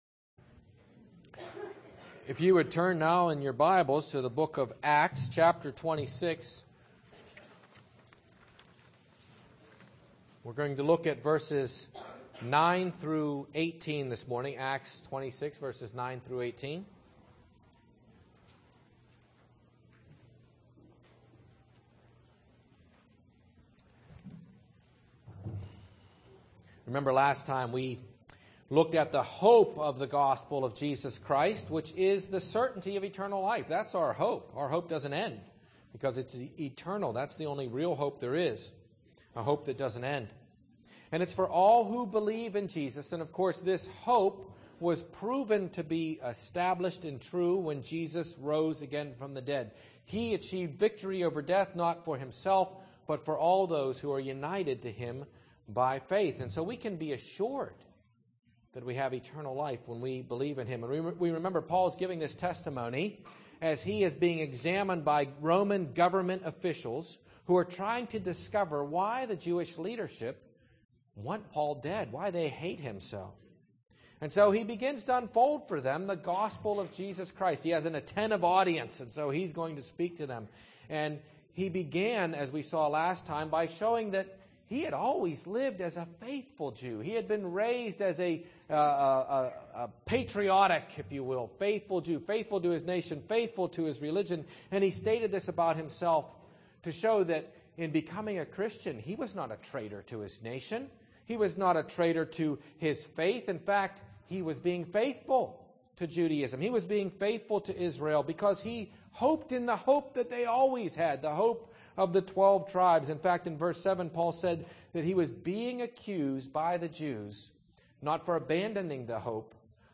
00:00 Download Copy link Sermon Text Acts 28:9–18